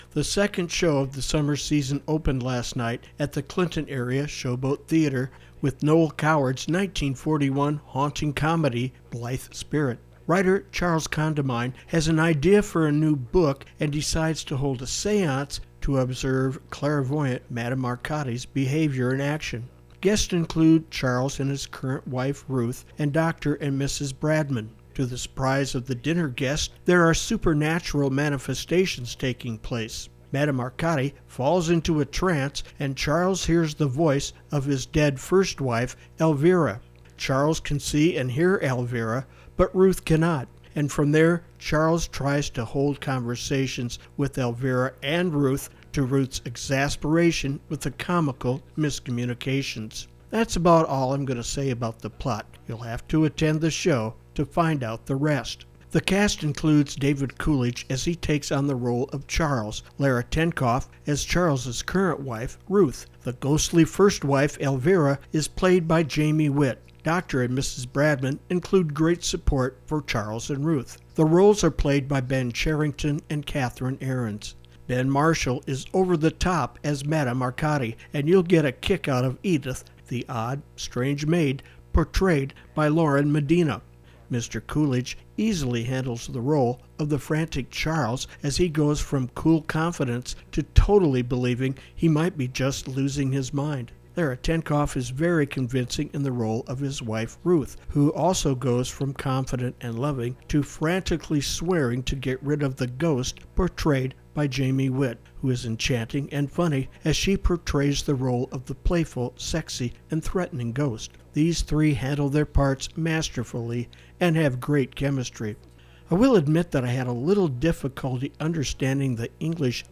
theater review